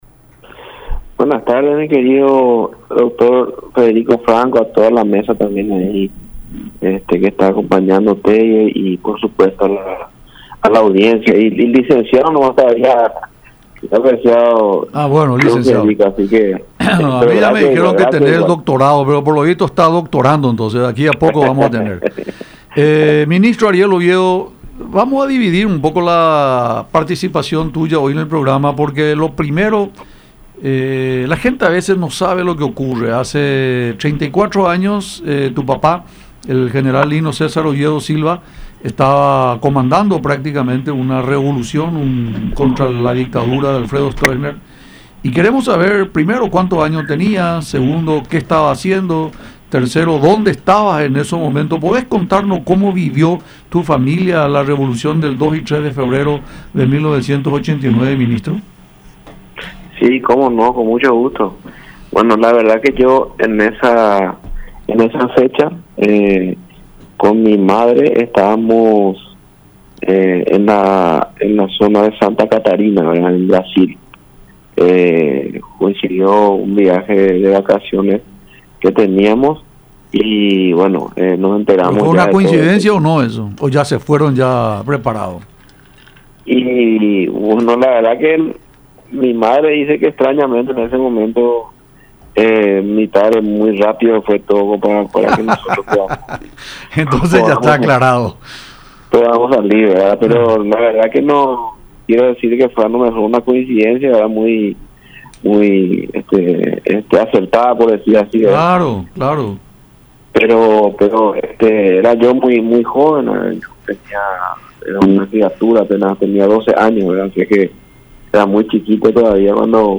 Entonces le comenté hacía una hora y media había conversado con él”, comenzó diciendo Oviedo en diálogo con Francamente por Unión TV y radio La Unión, recordando que su padre estaba en el departamento de Concepción, donde estaba realizando su campaña presidencial para el 2013.